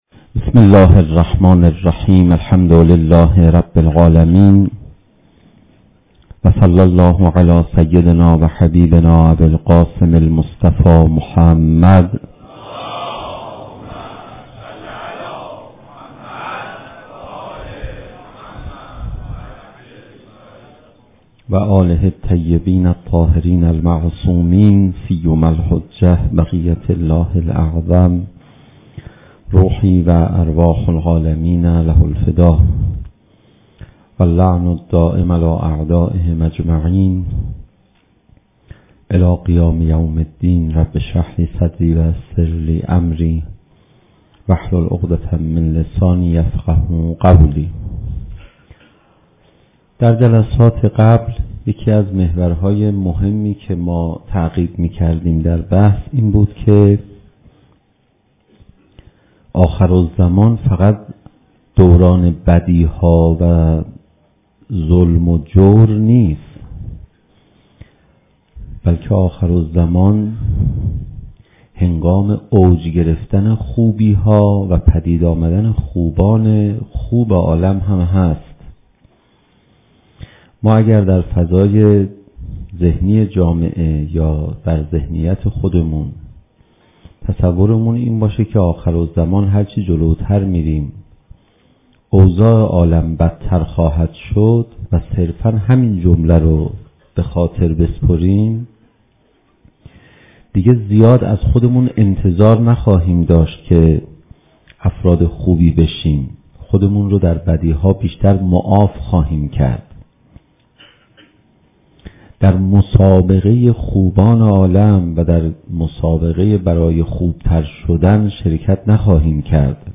زمان: 49:14 | حجم: 11.5 MB | تاریخ: 1393 | مکان: حسینیة آیت الله حق شناس